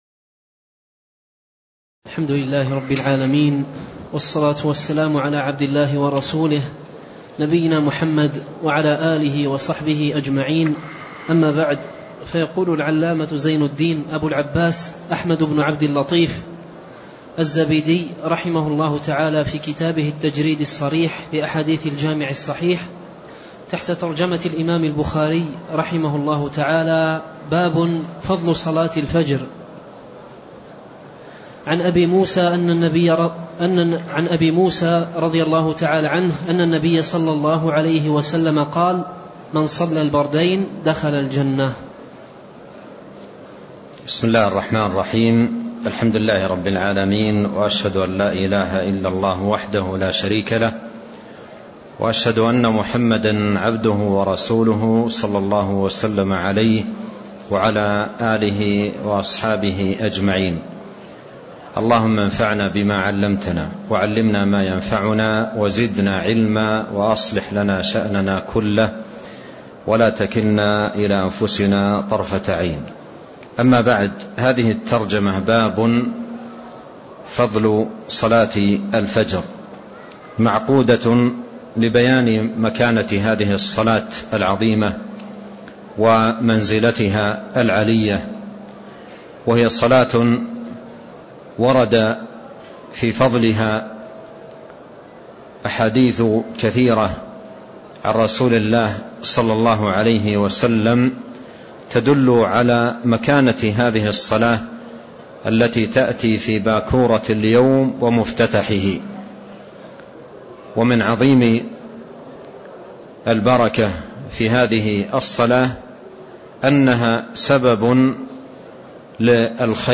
تاريخ النشر ١٧ ربيع الأول ١٤٣٤ هـ المكان: المسجد النبوي الشيخ